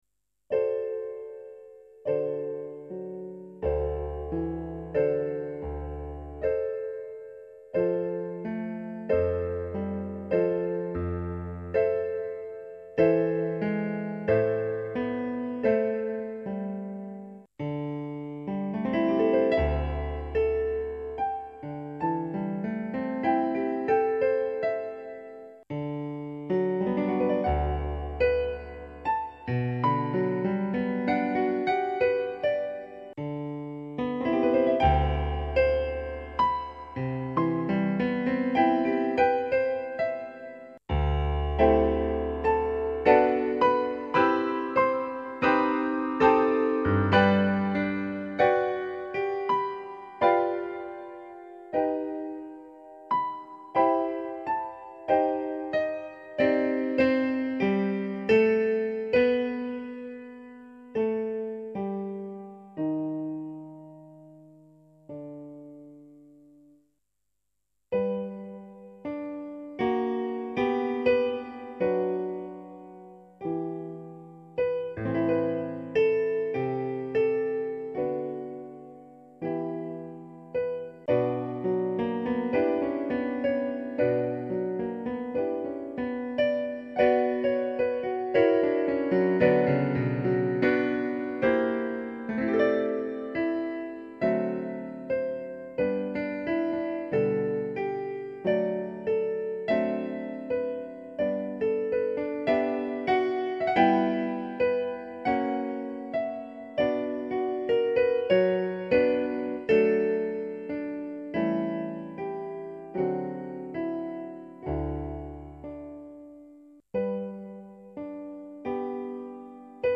Romanza degli Addii (versione pianistica)